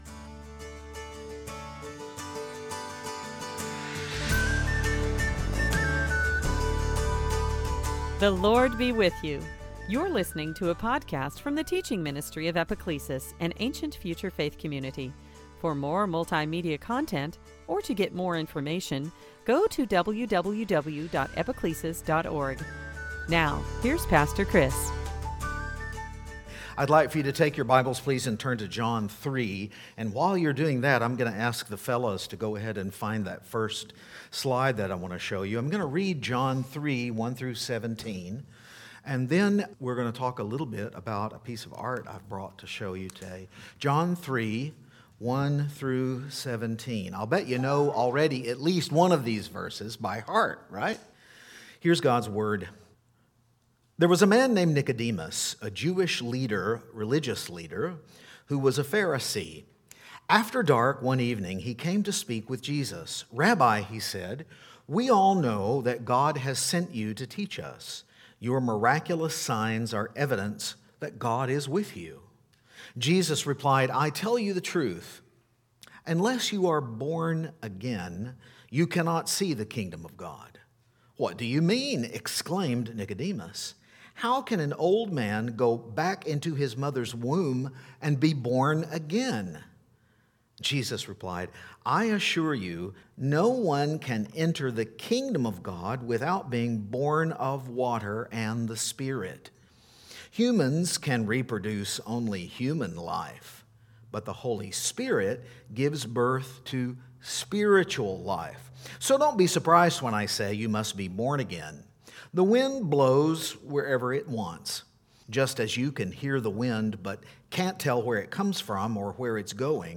Series: Sunday Teaching
Service Type: Lent